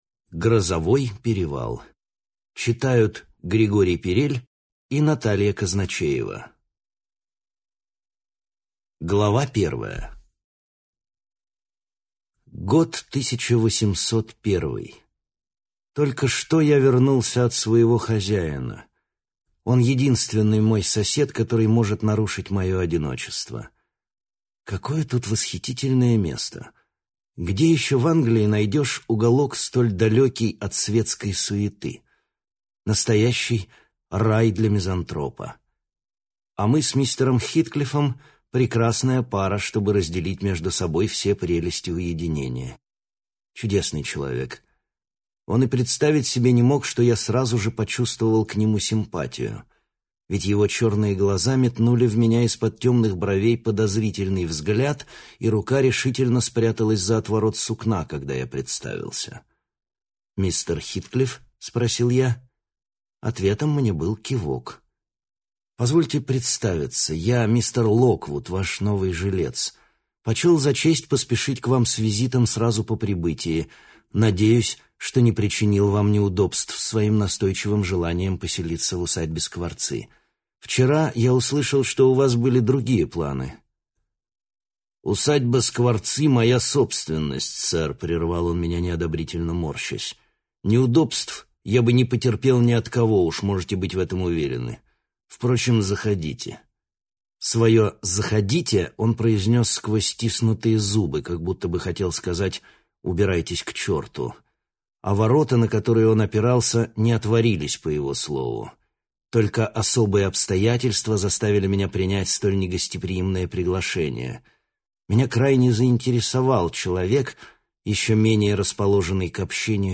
Аудиокнига Грозовой перевал | Библиотека аудиокниг
Прослушать и бесплатно скачать фрагмент аудиокниги